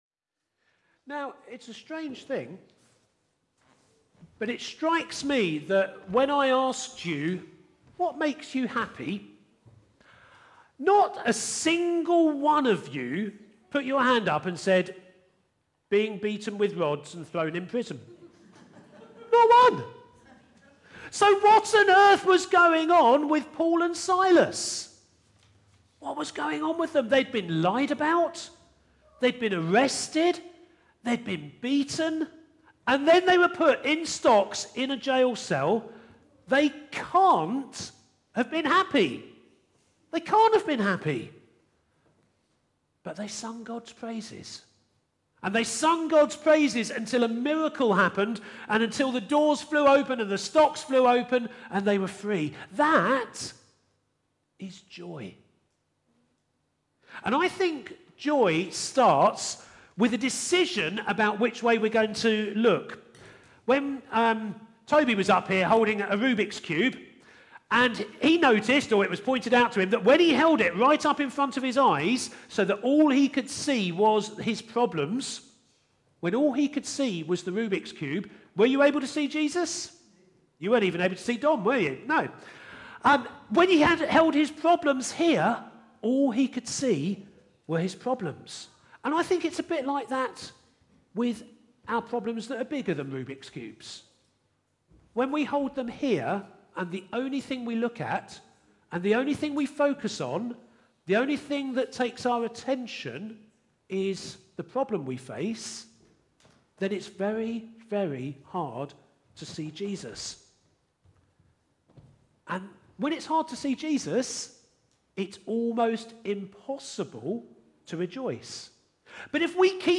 From Series: "Stand Alone Sermons 2025"